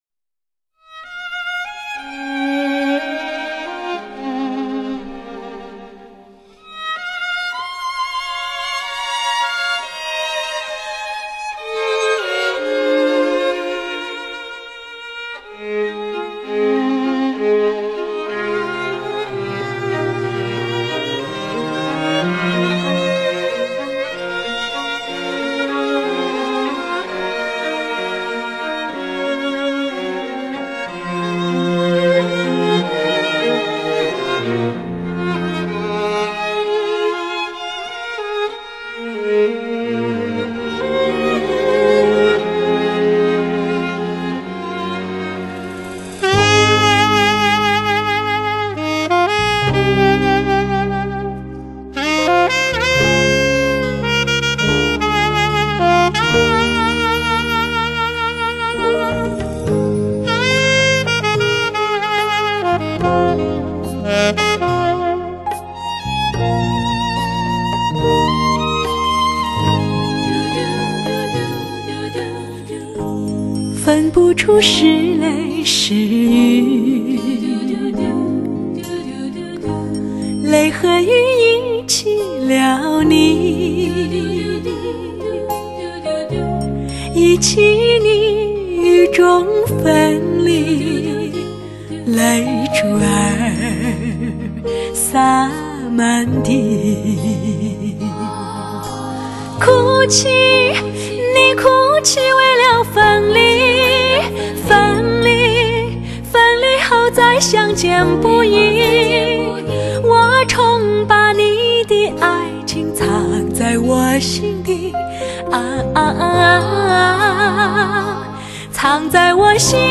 注：专辑采用联唱的方式，所有曲目整合在四大段歌曲中